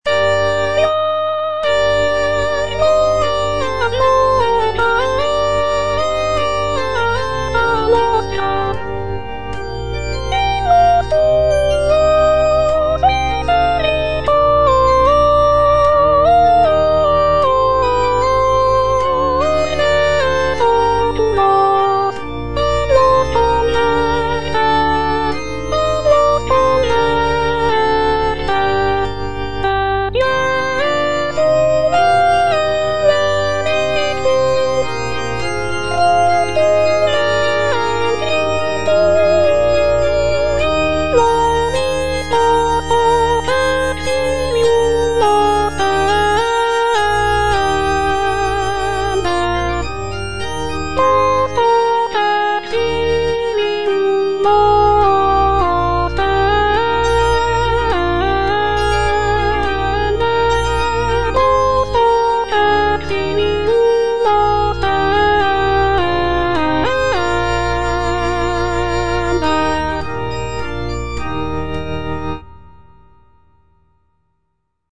Soprano (Voice with metronome) Ads stop
sacred choral work